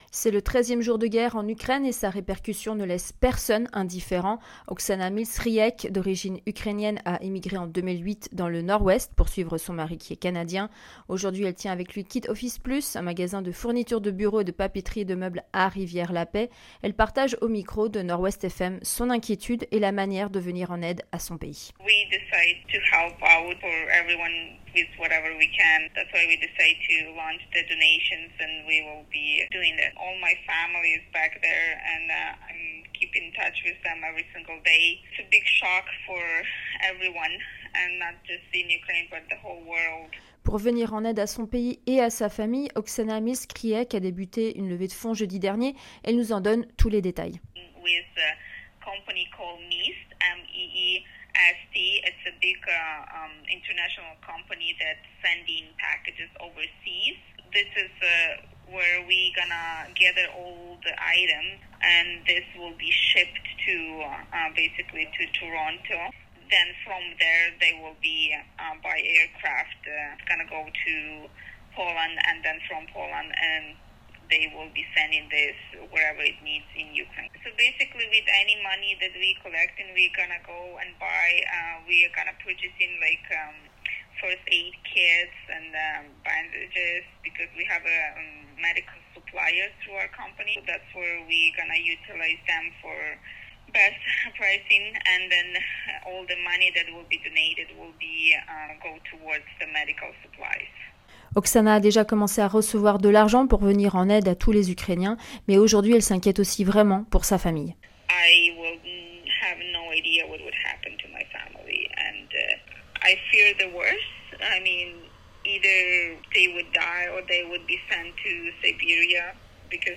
Un reportage de notre journaliste